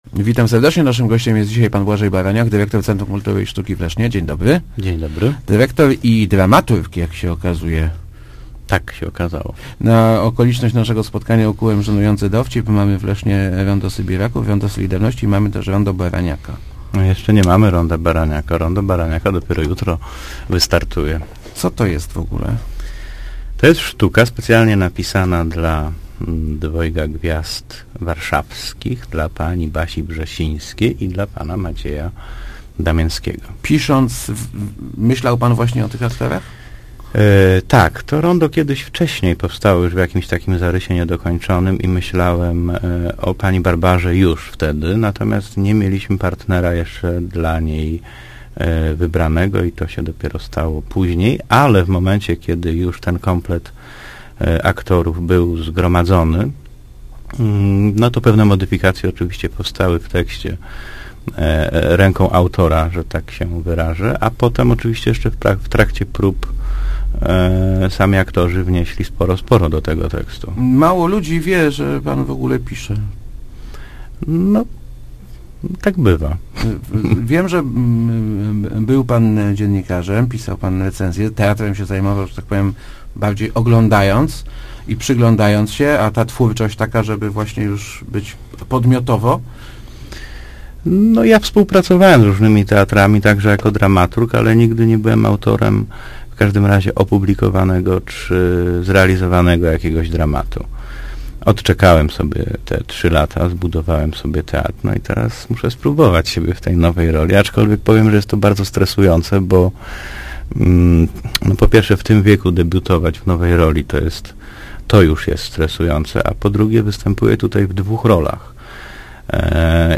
Pisałem ją z myślą o tych aktorach - mówił w Rozmowach Elki autor.